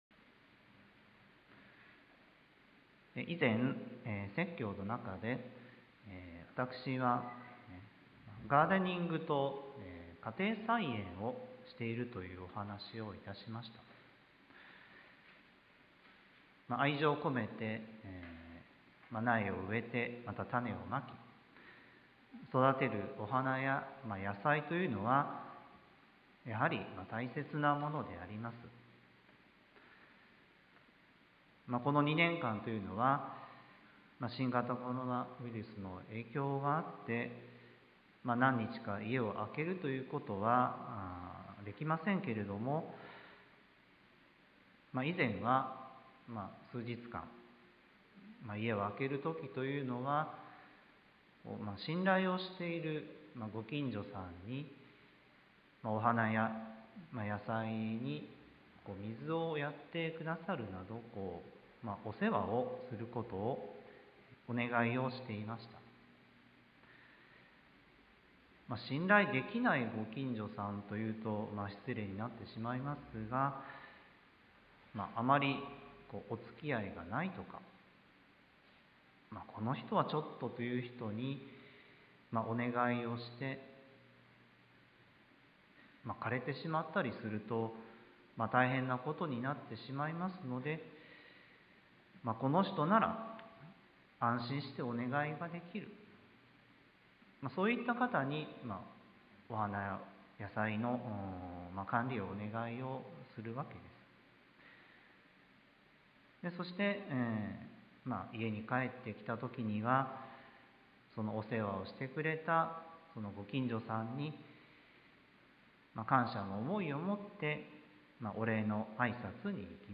sermon-2022-01-23